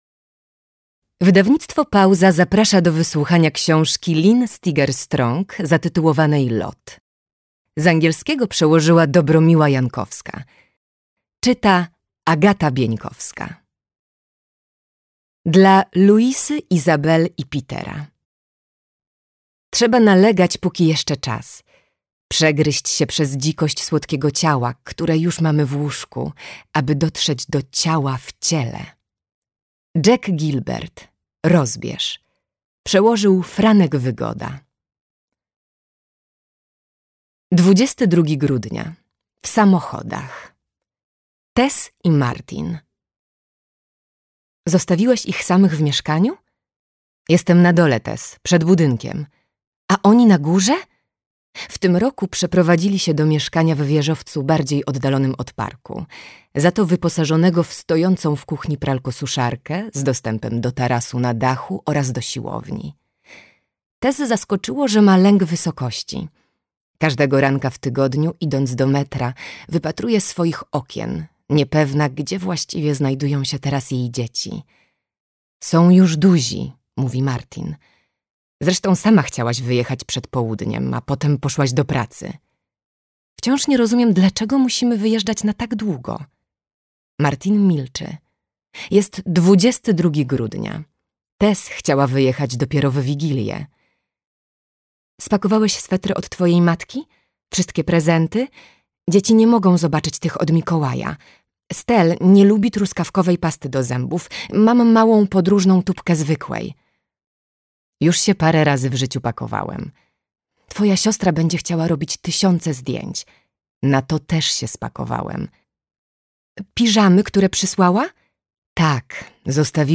AUDIOBOOK Tłumaczenie